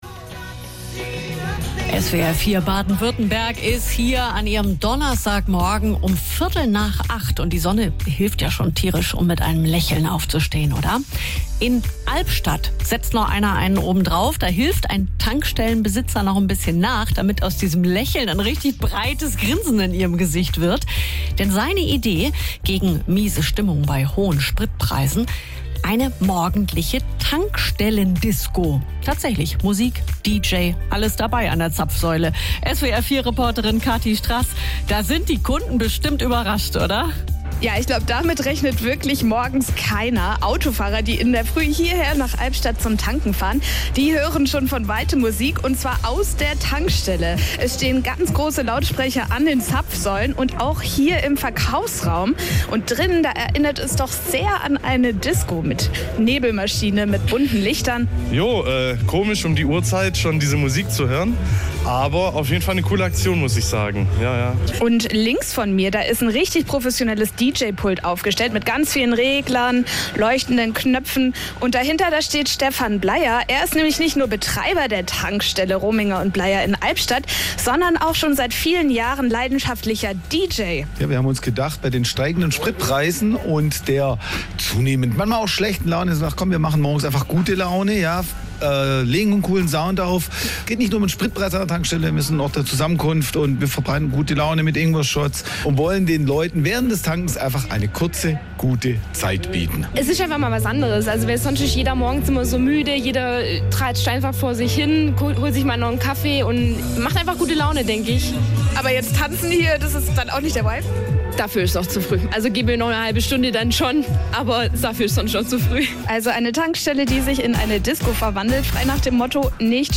Verschlafene Autofahrerinnen und Autofahrer, die nur kurz zum Tanken fahren wollten, trauen ihren Augen nicht: Schon von weitem ist laute Musik zu hören.
Mit Ingwer-Zitronen-Shot und tanzbarer Musik in den Tag starten: Einmal im Monat wird die Tankstelle in Albstadt-Ebingen zur Disco.